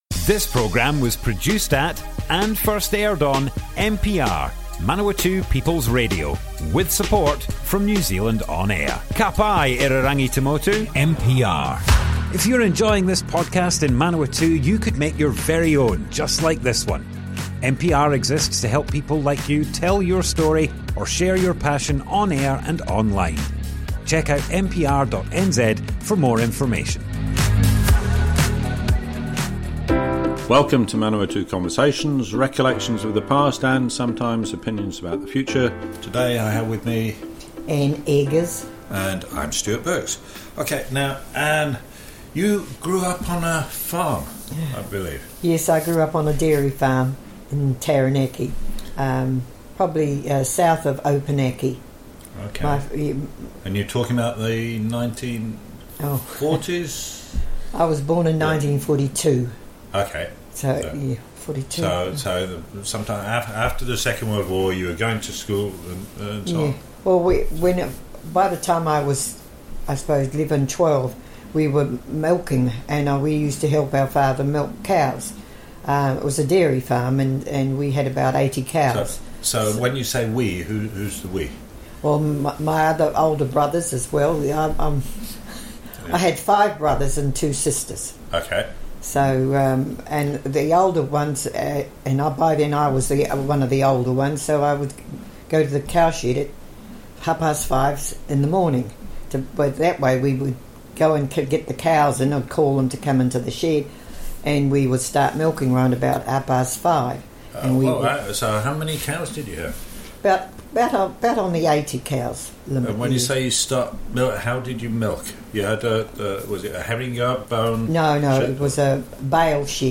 Early years and travels, - Manawatu Conversations Object type Audio More Info → Description Broadcast on Manawatu People's Radio, 12th September 2023, Part 1 of 2. Grew up on a dairy farm. 1950s, milking cows before and after school.
oral history